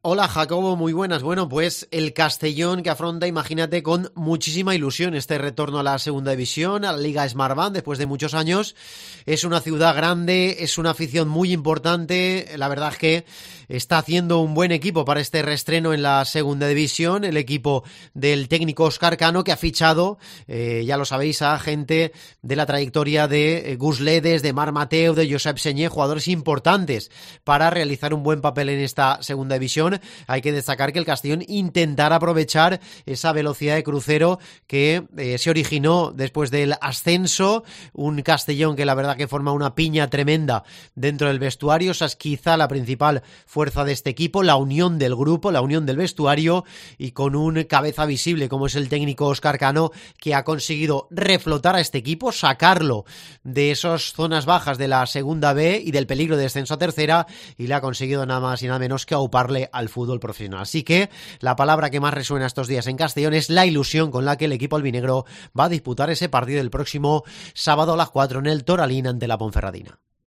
Escucha aquí la última hora del rival de la Ponferradina en la primera jornada de la temporada 2020-2021 de LaLiga SmartBank. Crónica